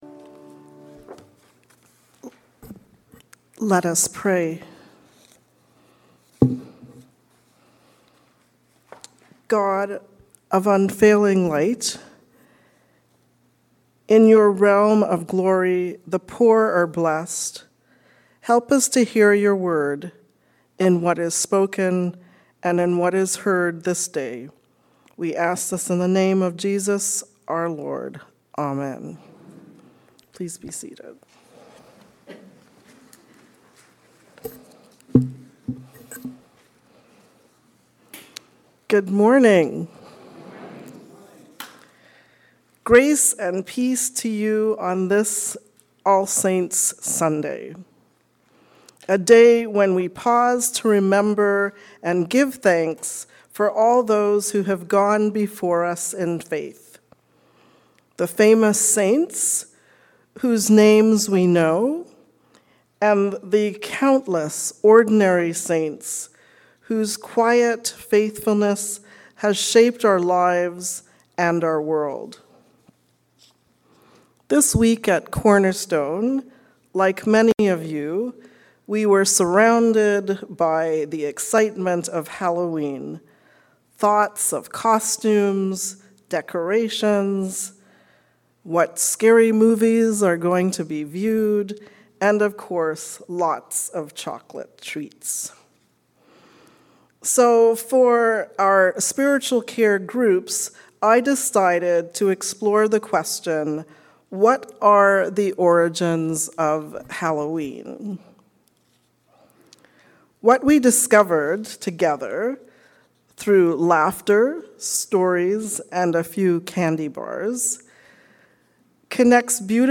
Sermon on Daniel 7:1-3, 15-18 Psalm 149 Ephesians 1:11-23 Luke 6:20-31
Sermon-2nd-November-2025.mp3